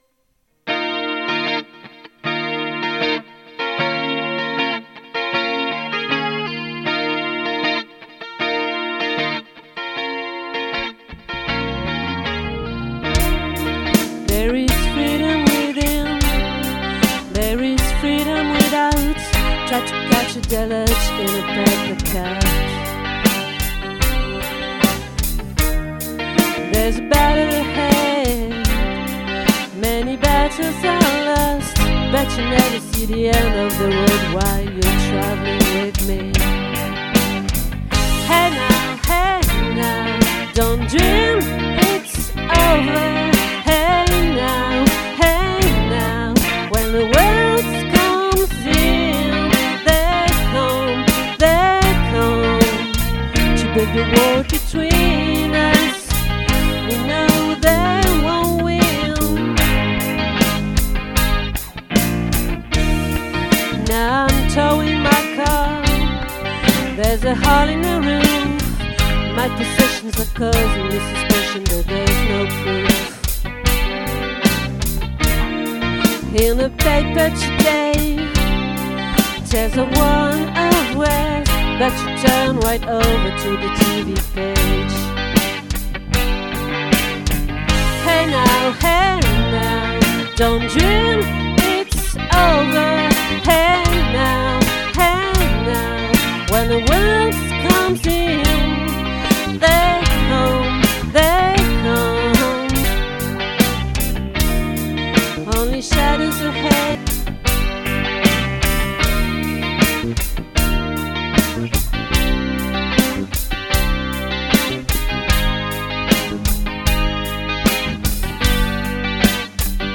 🏠 Accueil Repetitions Records_2022_02_17